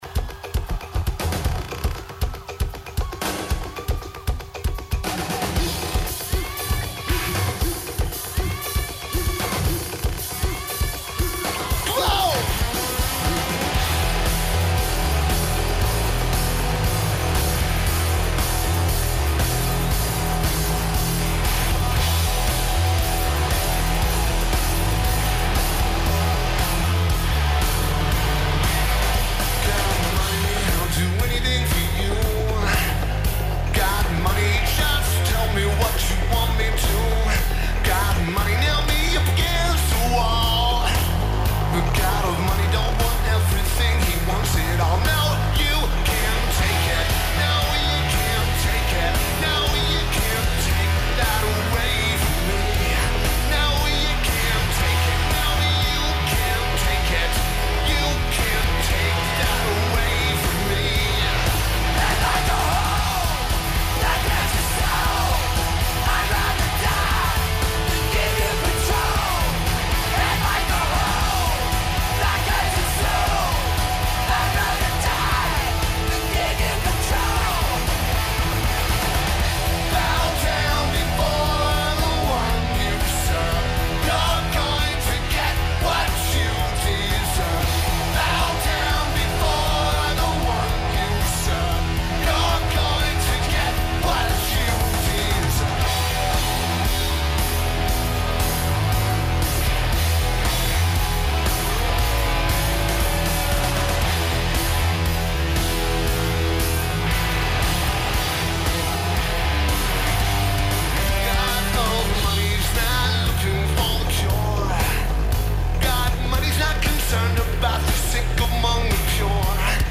Arena Santiago
Taper: FM Broadcast
Lineage: Audio - PRO (FM Broadcast)